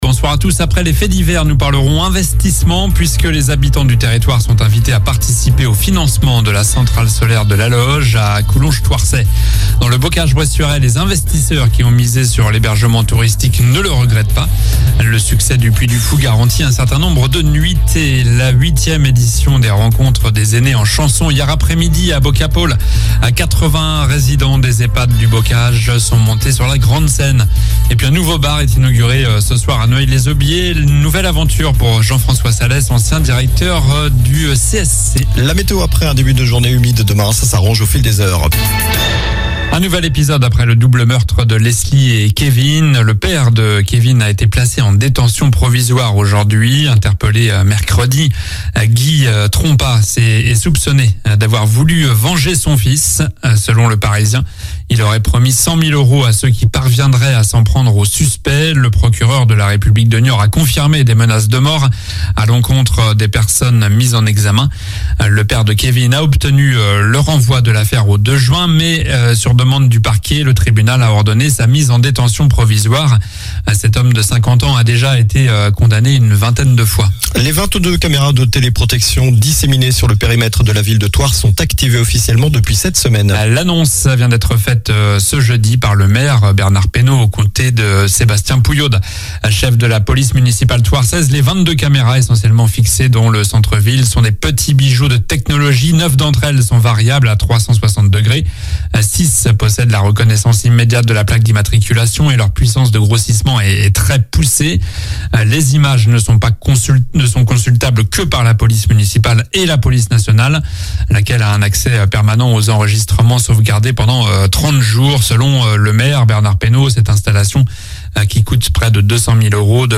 Journal du jeudi 04 mai (soir)